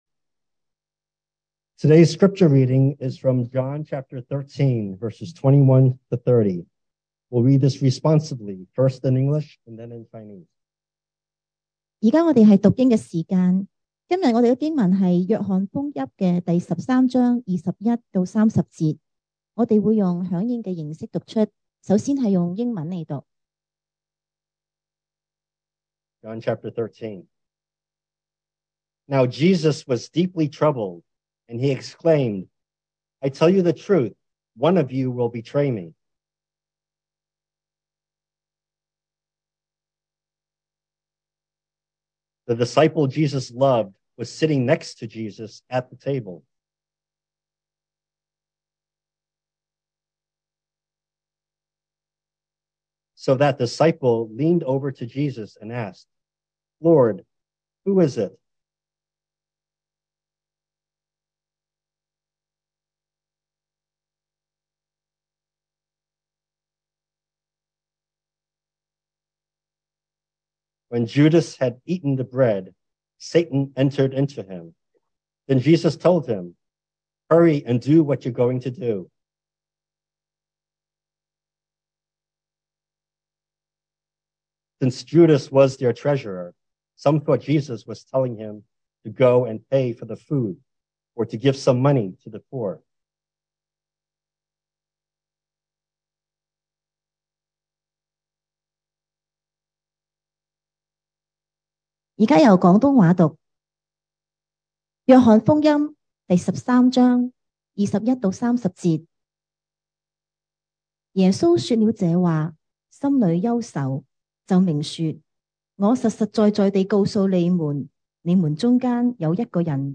2023 sermon audios
Service Type: Sunday Morning